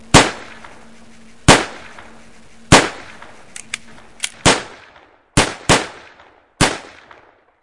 三发子弹，重新装弹
描述：三声枪响，然后重新装弹，再开四枪。
Tag: 刘海 重装 系列 射击